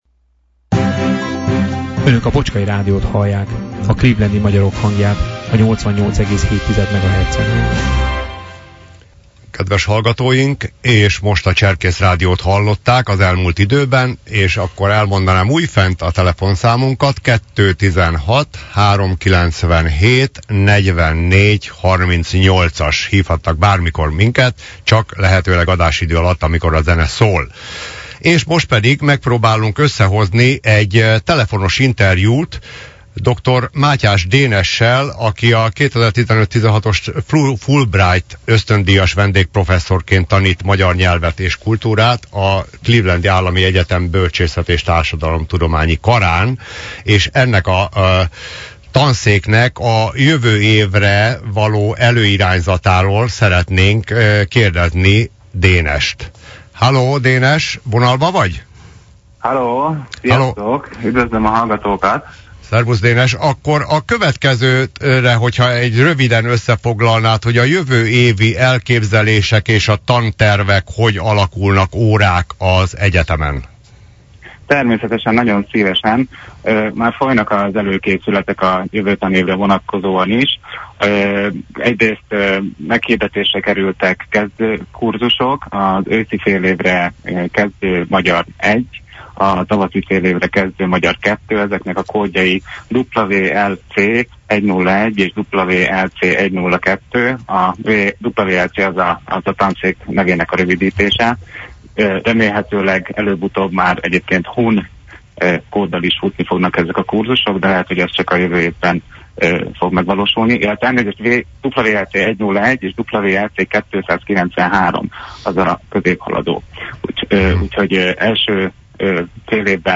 Vele készítettünk a március 6-i élő adásunkba egy  telefonos interjút. Először arról kérdeztük, hogy melyek az egyetem jövő évi tervei a kurzusok tekintetében.